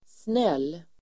Uttal: [snel:]